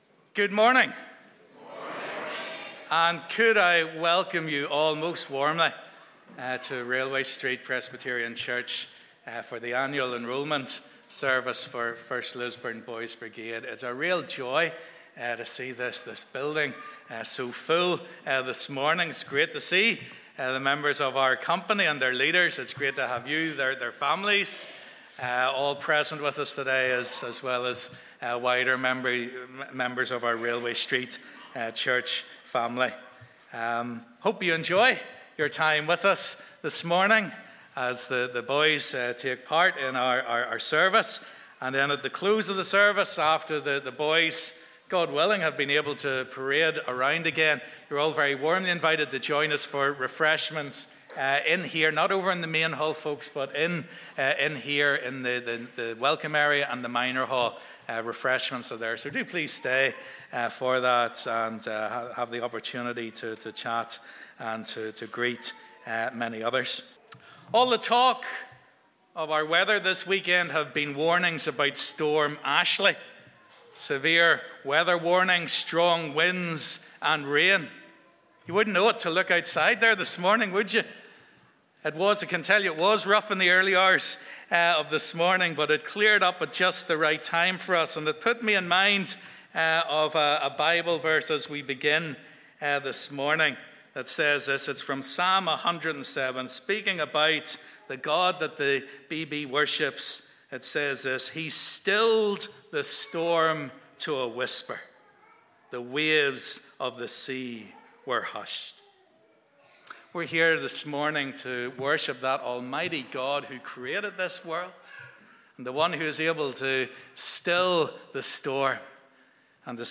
'1st Lisburn Boys Brigade Annual Service Of Enrolment'
For over 100 years 1st Lisburn Boys Brigade Company has been an integral part of the life and witness of Railway Street Presbyterian Church. It is a joy every autumn to have the members of our BB Company and their families join us for their annual service of Enrolment.
Morning Service